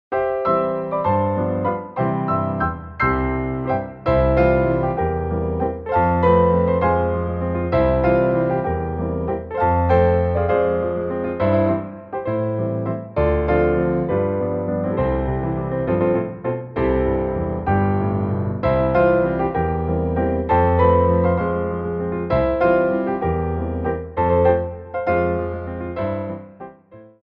Grands Battements / Grands Battements en Clôche
3/4 (16x8)